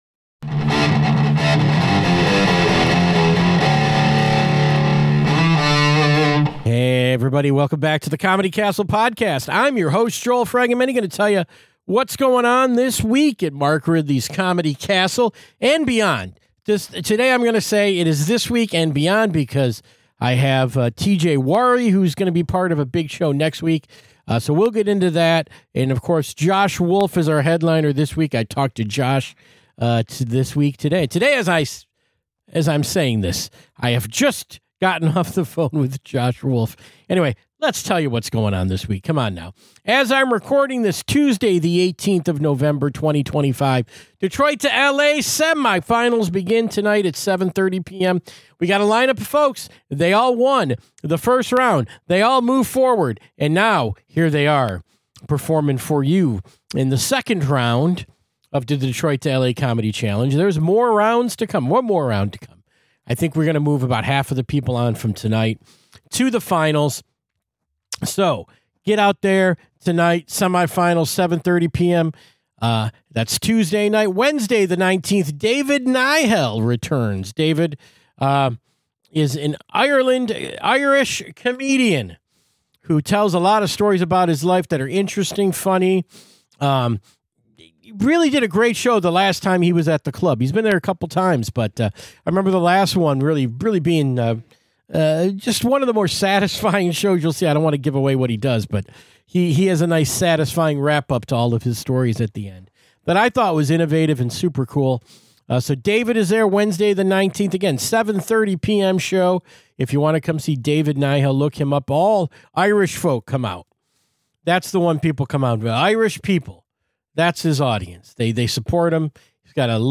Two fresh interviews this week, as weekend headliner Josh Wolf brings “The Campfire Tour” to The Comedy Castle.